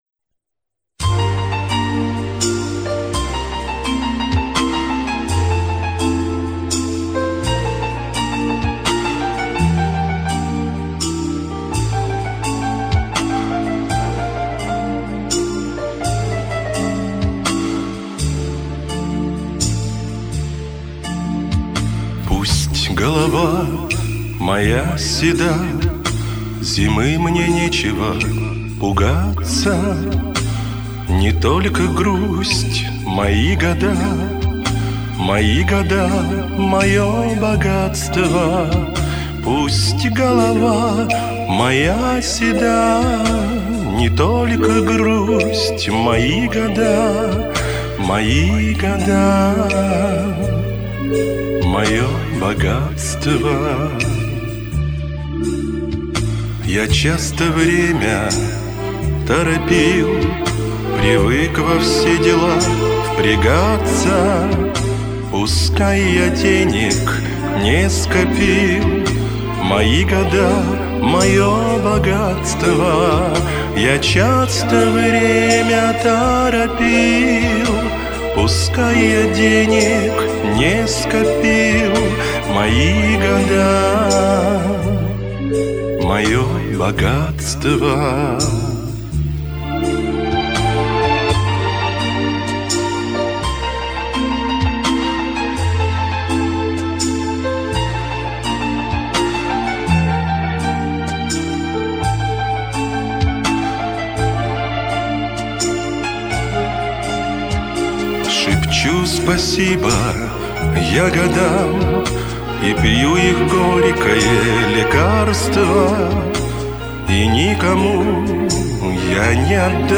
Вторая песня...конечно красиво спета...
Хорошо поет, правильно, запись хороша.